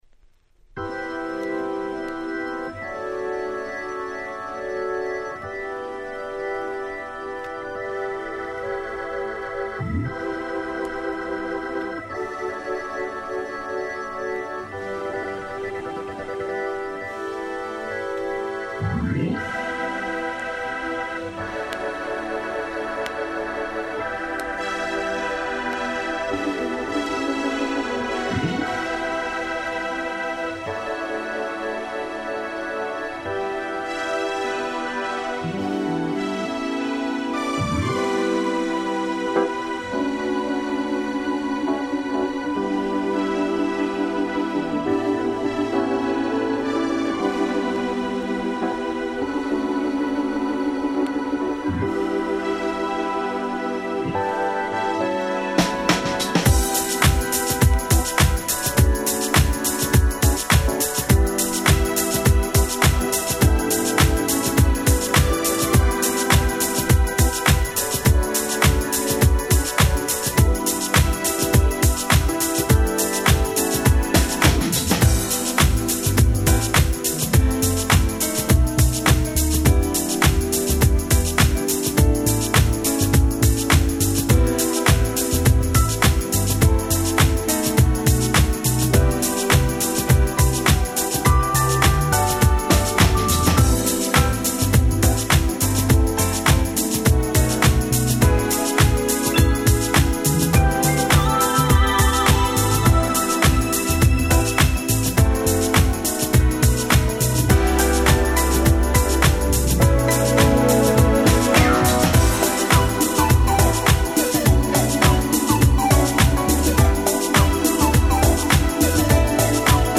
Acid Jazz Classics !!
Europe物にも通ずるあのBeat感、さらには洗練されたMelody…もう言うこと無しです。